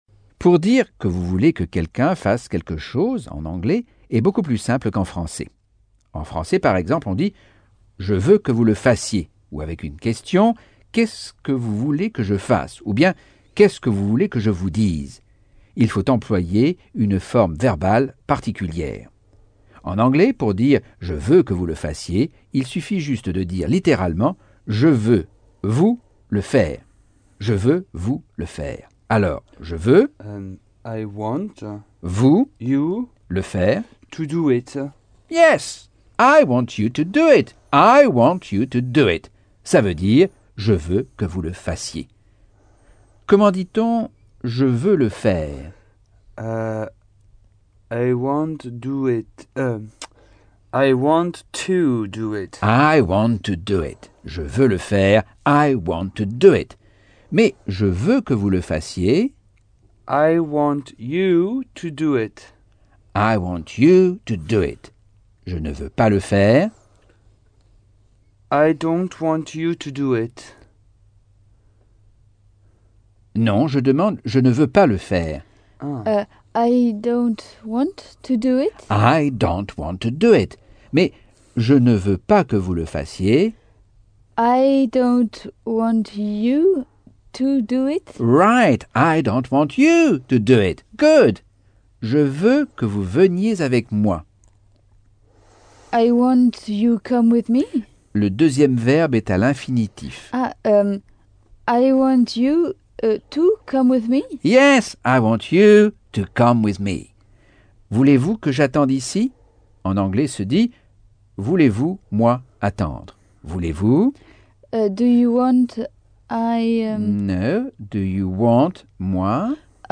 Leçon 2 - Cours audio Anglais par Michel Thomas - Chapitre 8